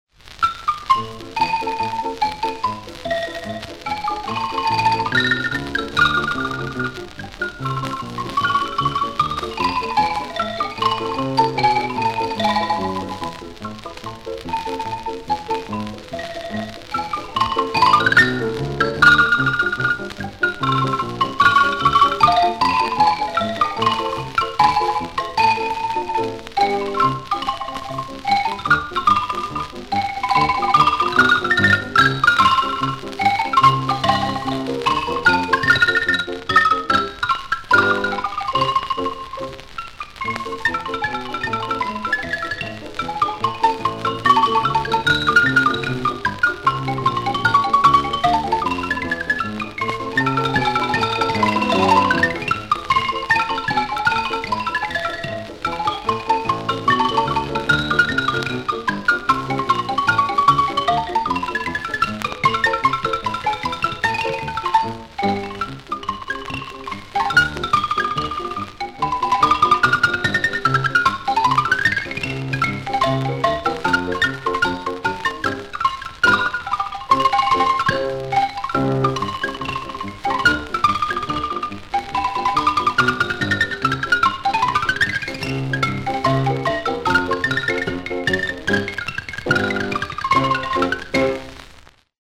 木琴二重奏